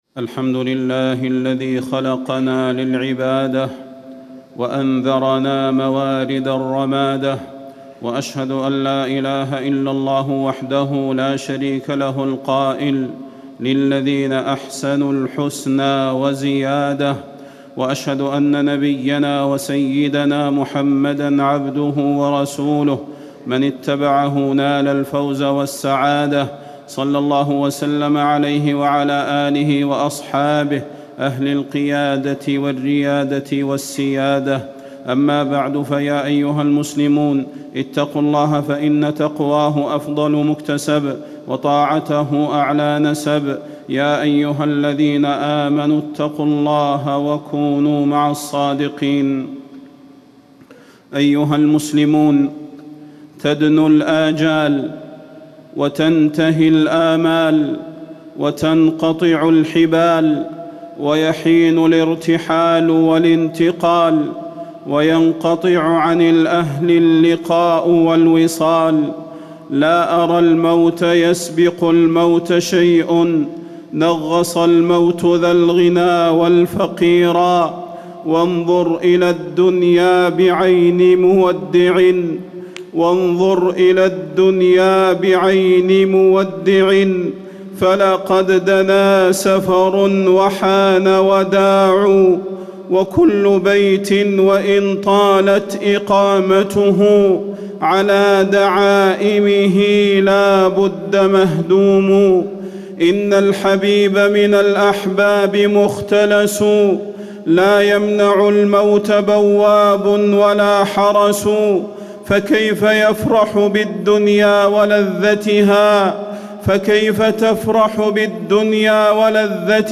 تاريخ النشر ٢٠ رجب ١٤٣٩ هـ المكان: المسجد النبوي الشيخ: فضيلة الشيخ د. صلاح بن محمد البدير فضيلة الشيخ د. صلاح بن محمد البدير نعيم الجنة الخالد